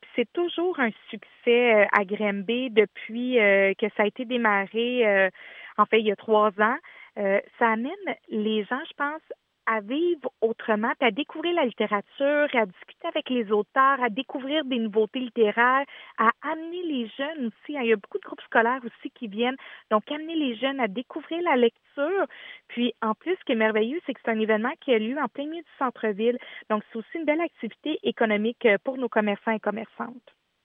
En entrevue avec le Service de nouvelles de M105, Julie Bourdon parle des bienfaits selon elle du Salon du livre autant sur les gens que les entreprises d’ici.